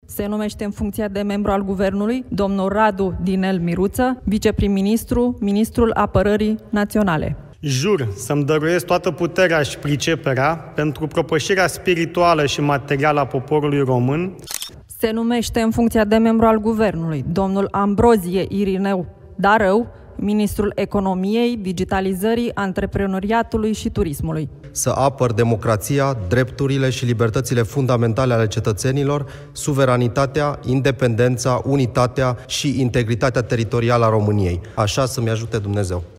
UPDATE 15:00. Radu Miruță și Irineu Darău au depus jurământul de învestitură
Ceremonia de învestire a celor doi miniştri USR a avut loc la Palatul Cotroceni.